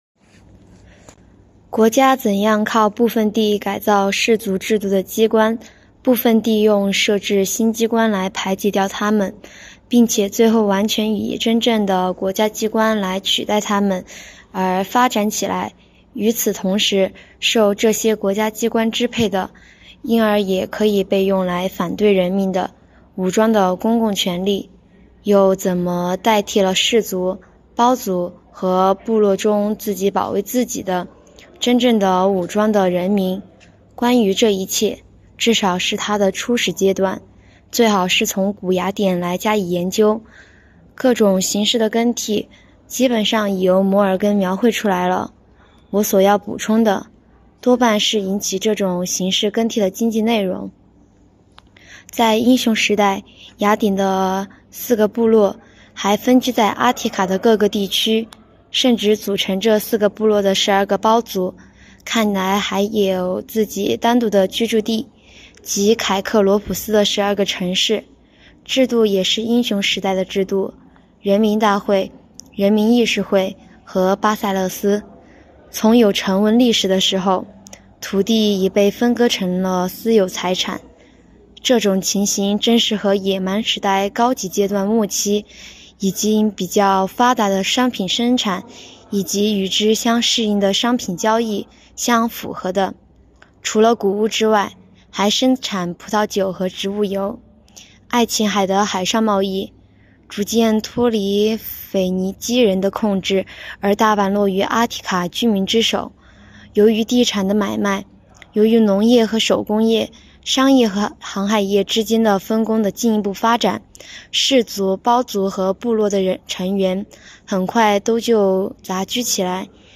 “读经典、悟原理”——2025年西华大学马克思主义经典著作研读会接力诵读（03期）